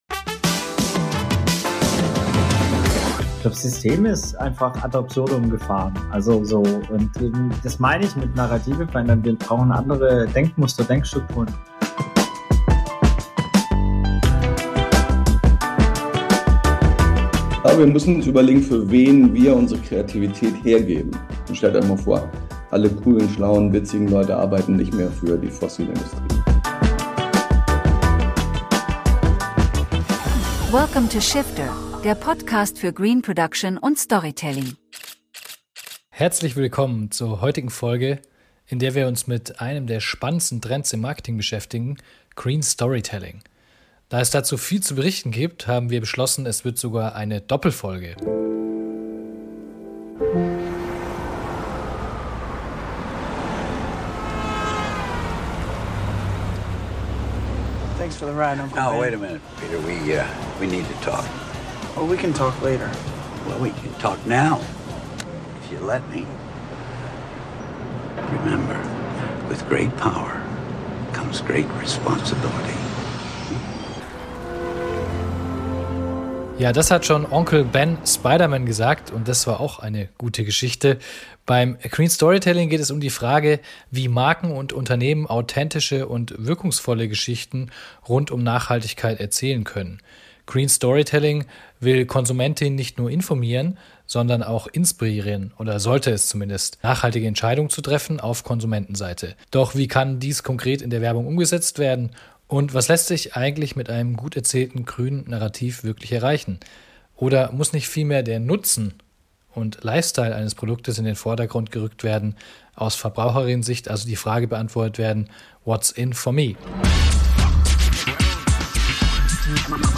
Gäste und spannende Stimmen aus Unternehmen, Agenturen und Kreation des ersten Teils dieser Doppelfolge sind: